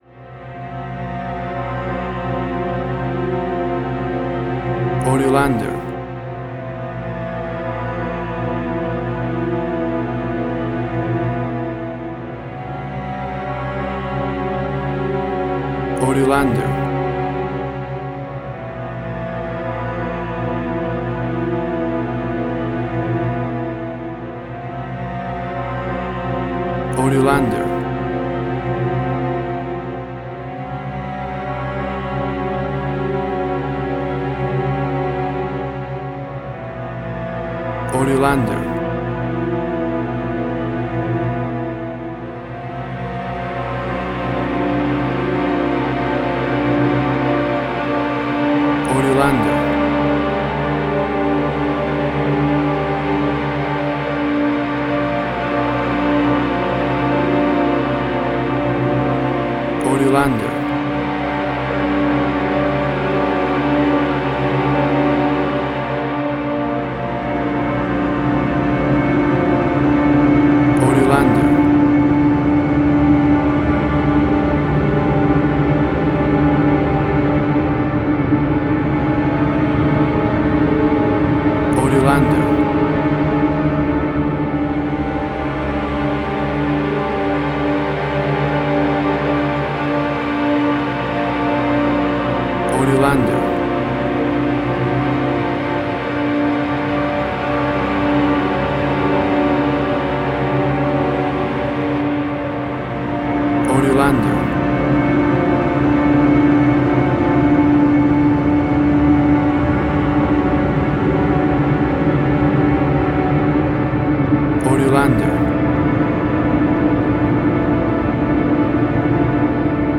Suspense, Drama, Quirky, Emotional.